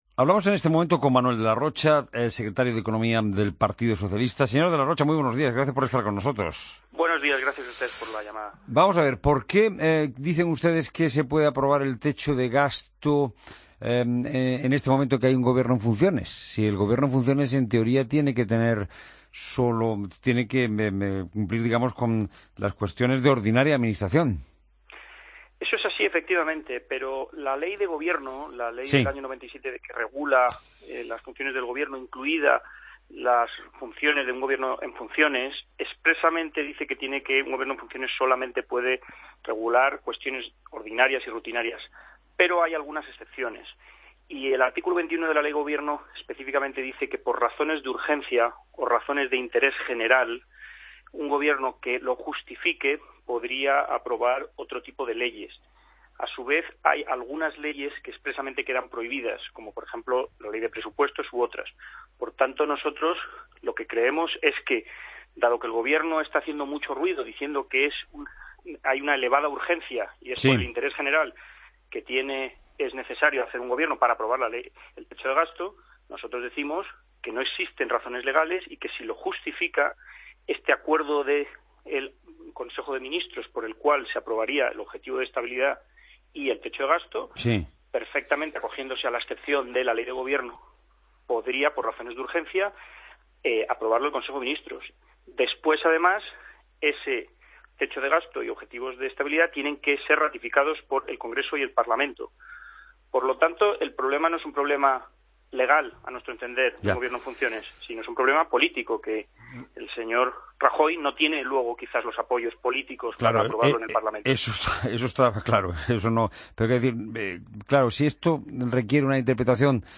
Entrevista al secretario de Economía del PSOE